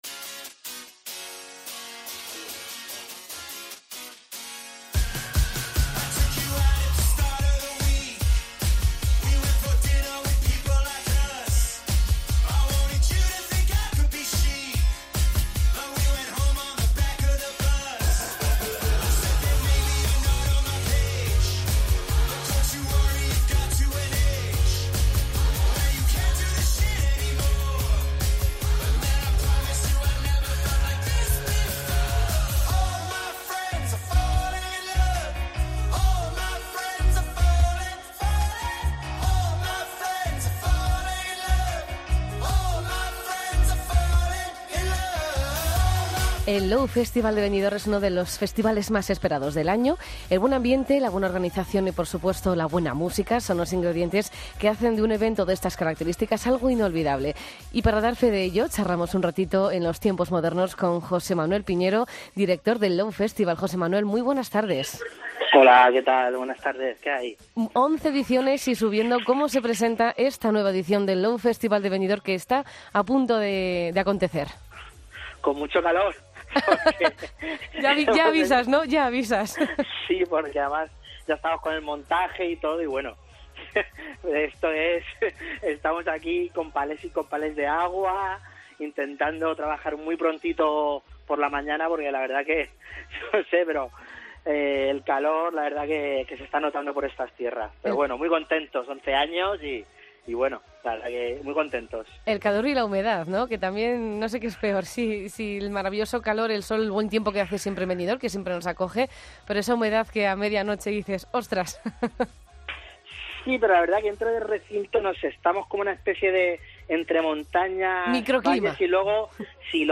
Nos atiende desde el recinto del festival porque ya están en plena faena, preparando todo para que del 26 al 28 de julio, disfrutemos de la undécima edición.